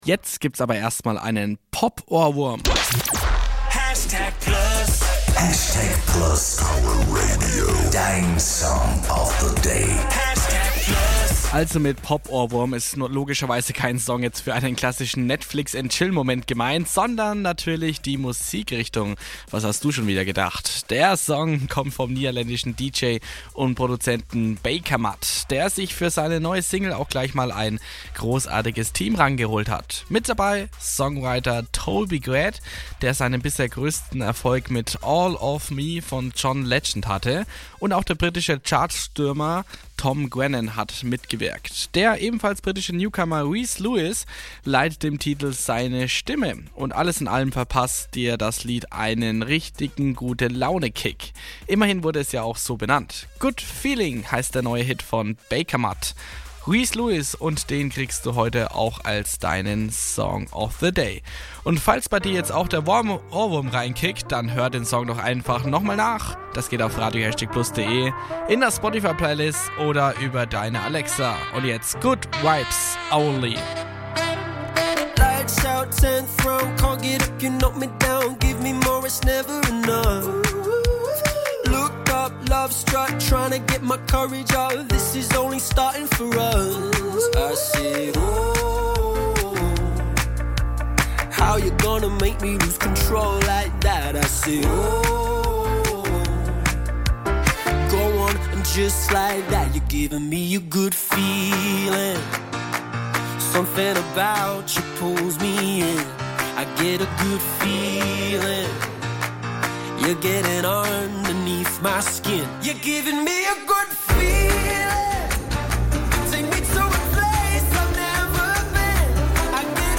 Jetzt gibt’s erst mal einen Pop-Ohrwurm.